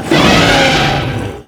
hurt4.wav